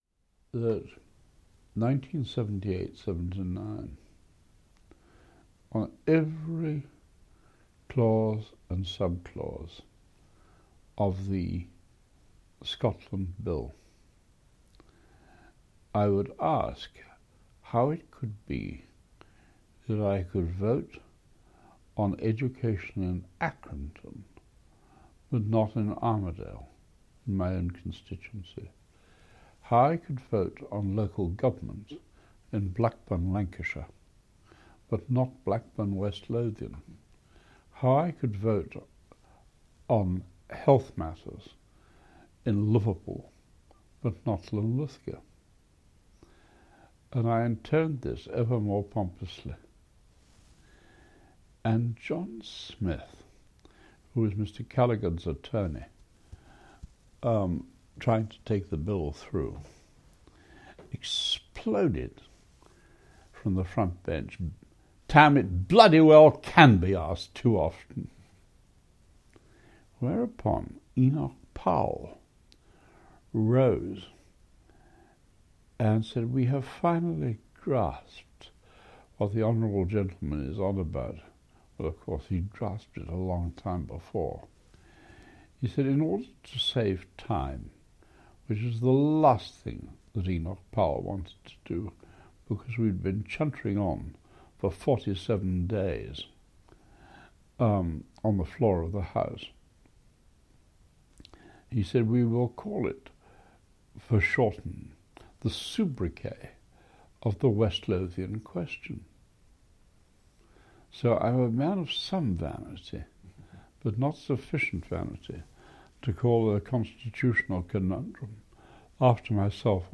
Even in the setting of an oral history interview he proved formidable: seizing our interviewer’s notes before starting, he often seemed to be conducting the interview himself!